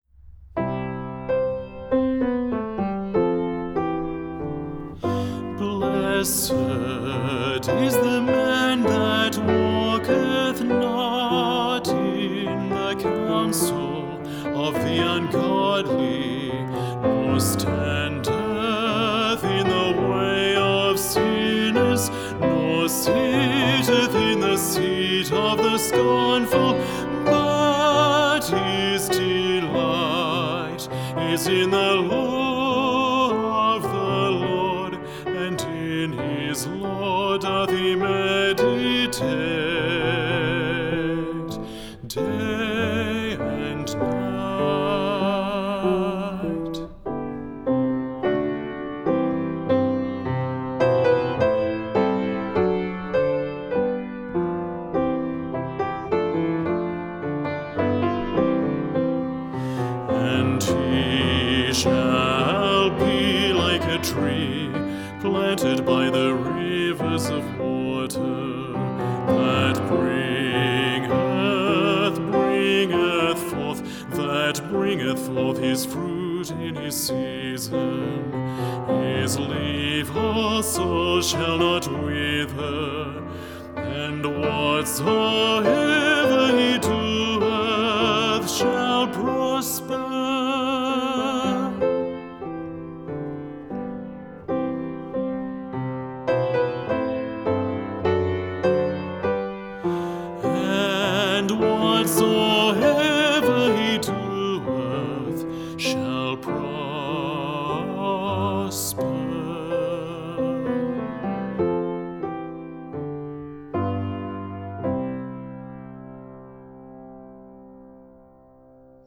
• 26 songs and 25 dialogue recordings.